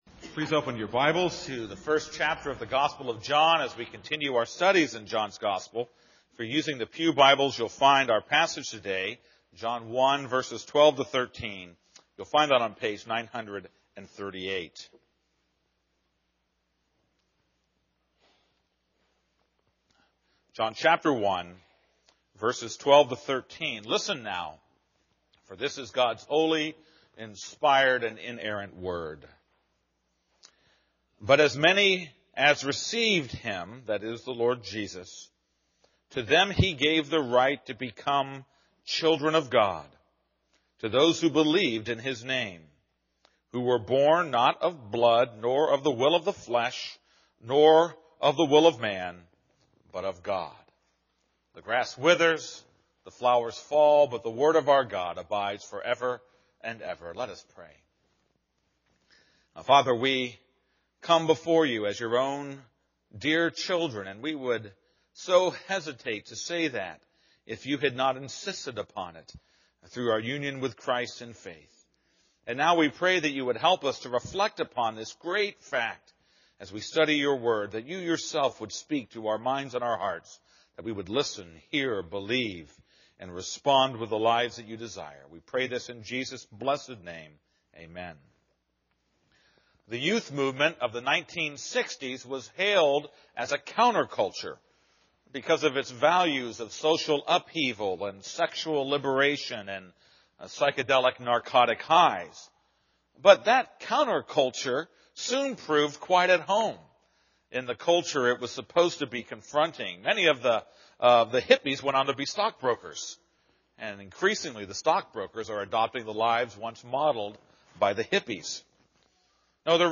This is a sermon on John 1:12-13.